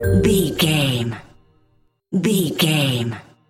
Aeolian/Minor
Slow
flute
oboe
strings
cello
double bass
percussion
violin
sleigh bells
silly
goofy
comical
cheerful
perky
Light hearted
quirky